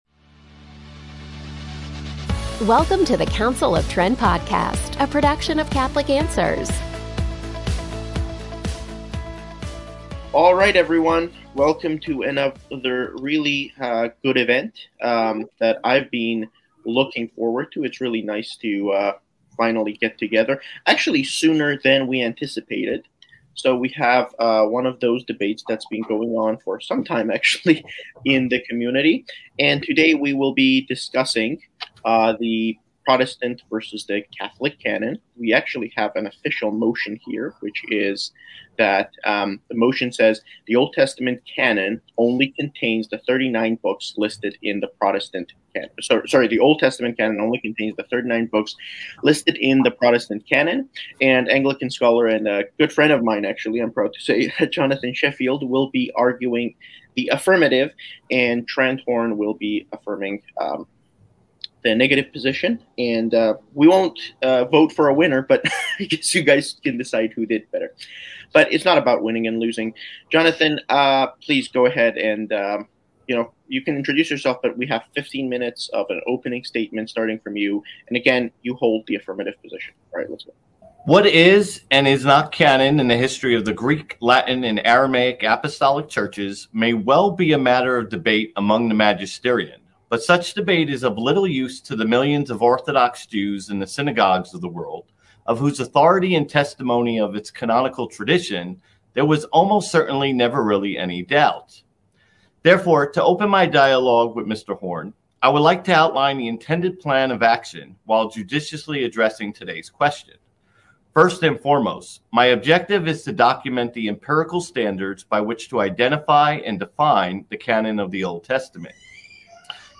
DEBATE: Should Christians Accept the Protestant Old Testament Canon?